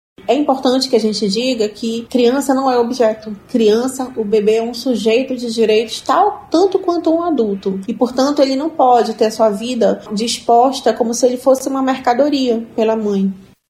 A juíza Rebeca de Mendonça Lima destaca a existência de amparo legal para que uma gestante ou mãe faça a entrega voluntária de um filho à adoção, amparo este previsto, inclusive, no Estatuto da Criança e do Adolescente, e faz um alerta.